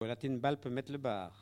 Enquête Arexcpo en Vendée
Locution